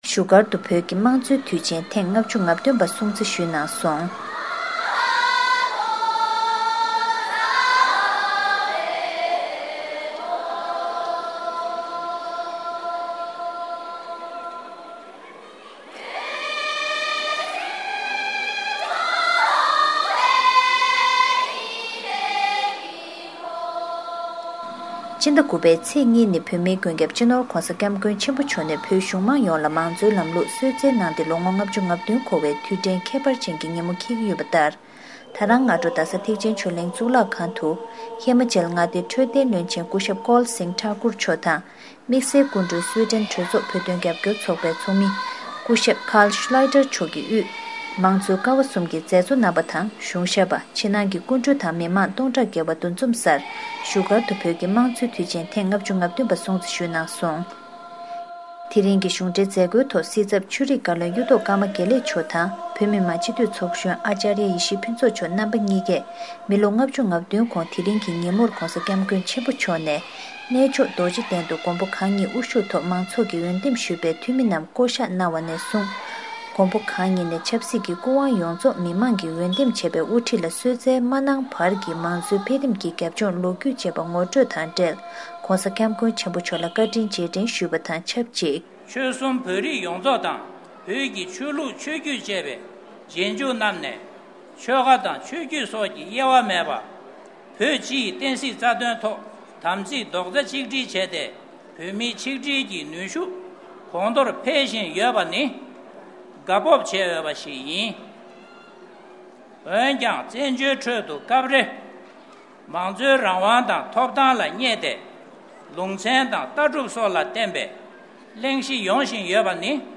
བཞུགས་སྒར་ནས་བཏང་བའི་གནས་ཚུལ་ཞིག